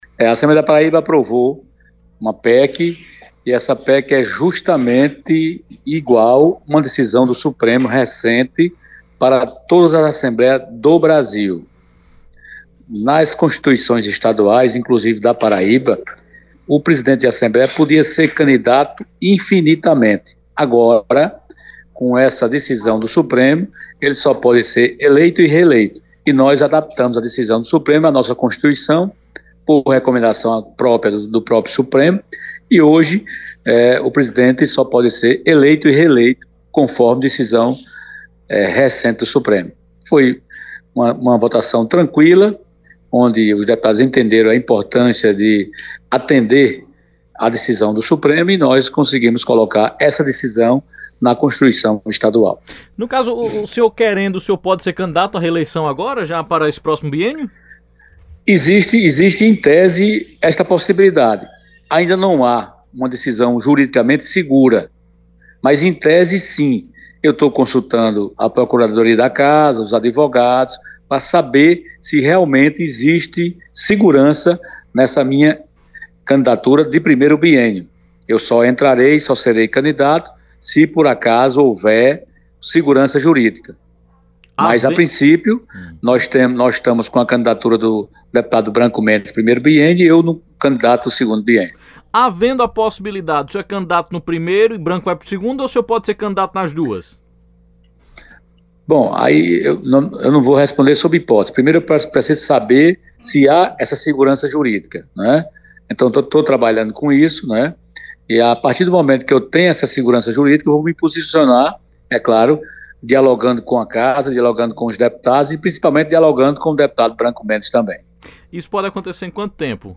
Os comentários do parlamentar foi registrado pelo programa Correio Debate, da 98 FM, de João Pessoa, nesta quarta-feira (21/12).